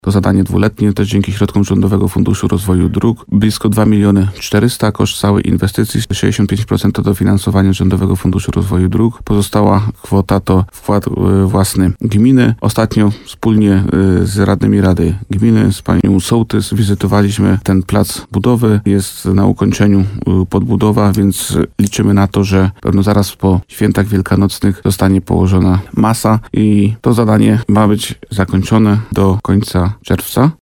Jak mówił w programie Słowo za Słowo w radiu RDN Nowy Sącz wójt Bogdan Łuczkowski, jedna z ważniejszych inwestycji drogowych, czyli remont w Owieczce, ma się zakończyć za kilka miesięcy.